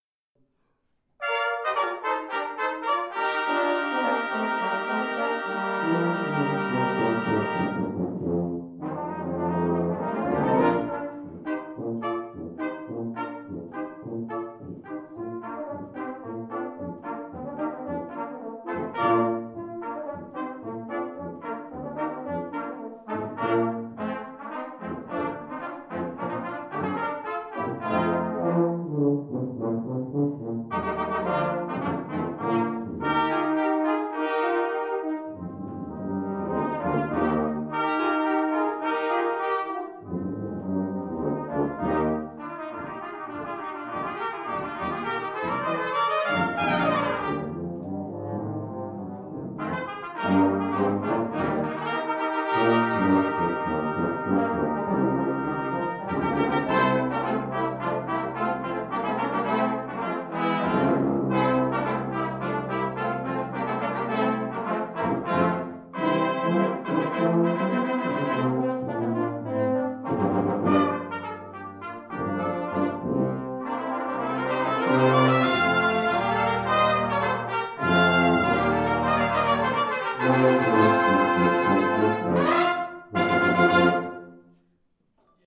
this is so cheerful